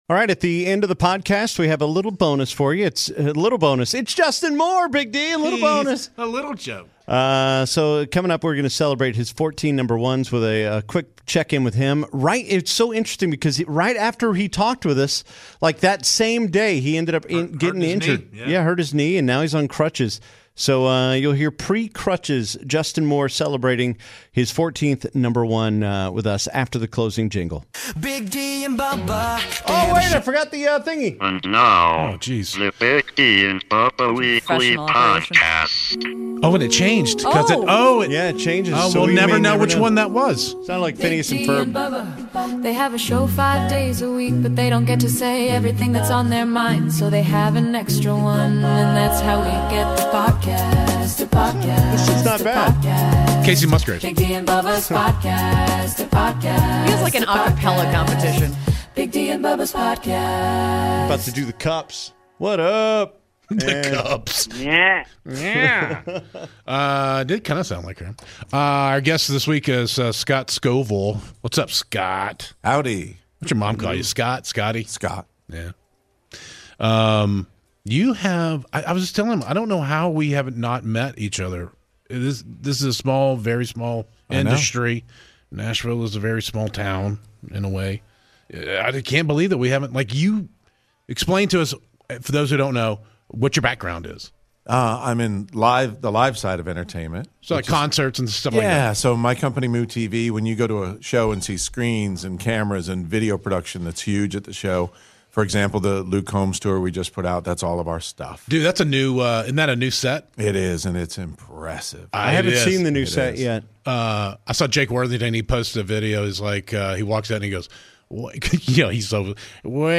Plus, we call Justin Moore and congratulate him on his latest chart-topper!